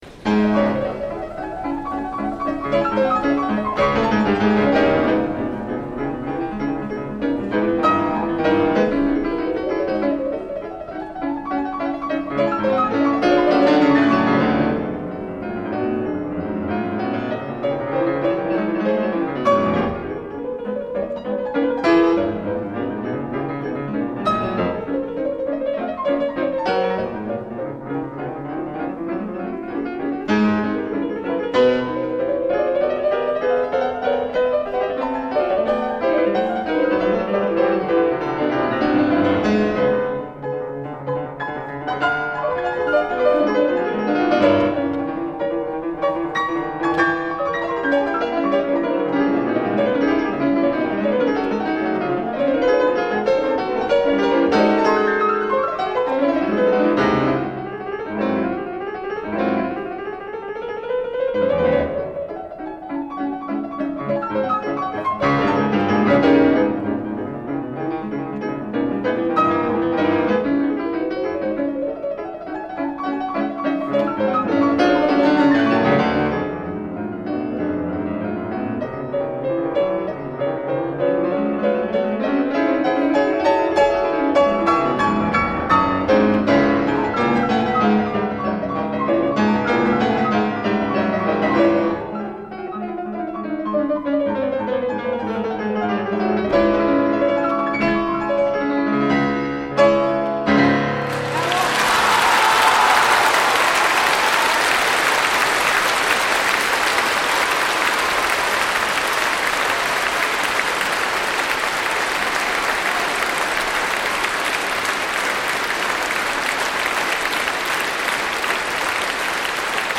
Крупнейший национальный правообладатель – фирма «Мелодия» выпустила ограниченным тиражом коллекционное собрание записей пианиста Святослава Рихтера к 100-летию со дня его рождения.
Бокс, выполненный в виде рояля, включает в себя 50 дисков с концертными и домашними записями великого пианиста.